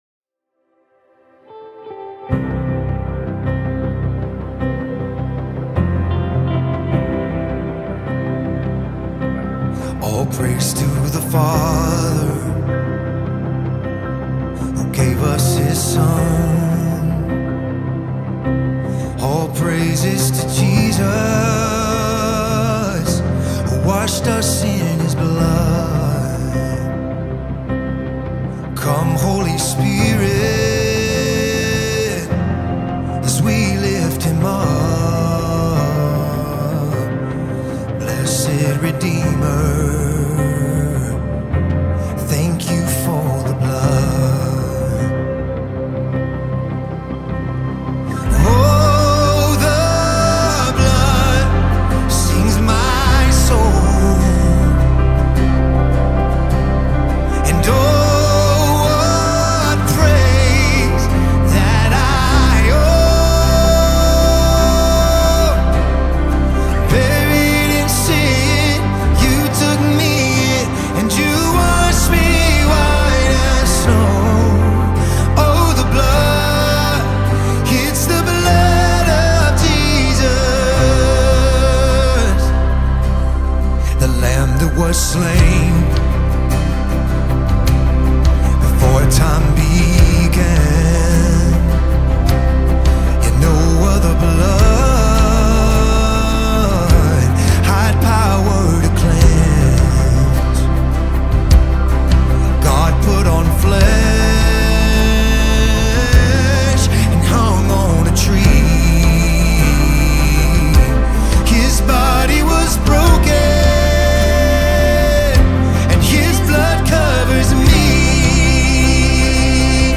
2025 single